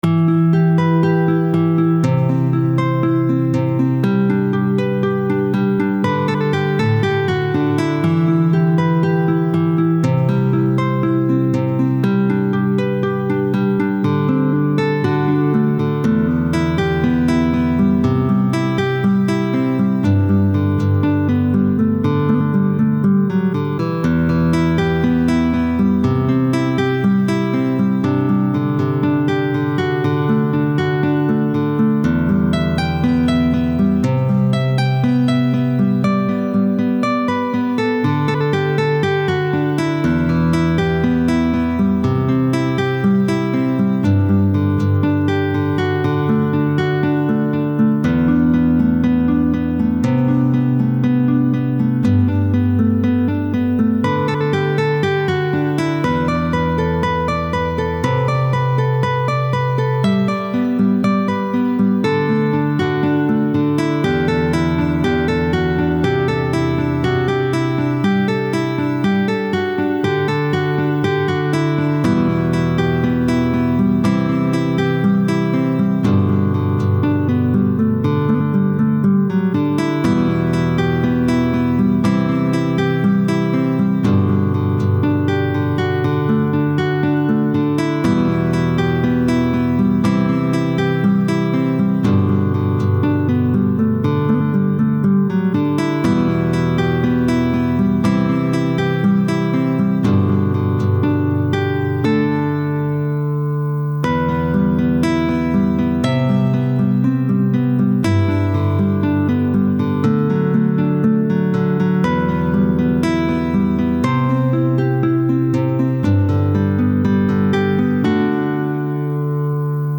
谱内音轨：木吉他
曲谱类型：指弹谱